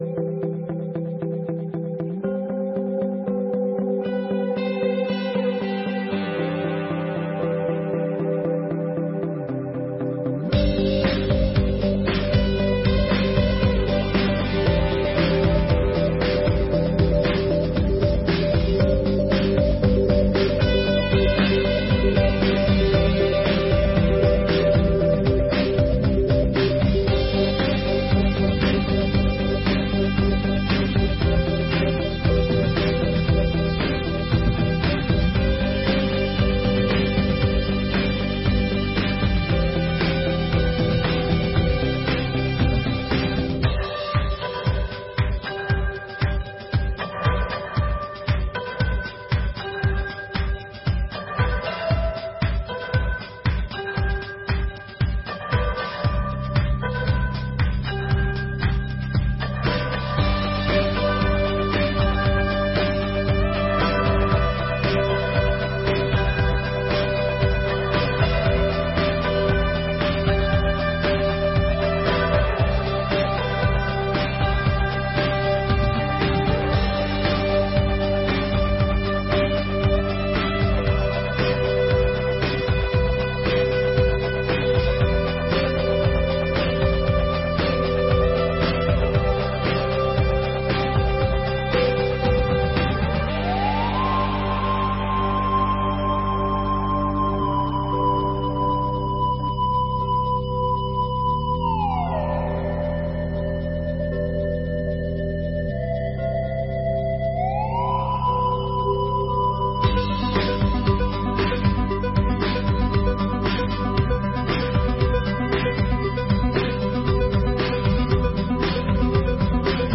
32ª Sessão Ordinária de 2023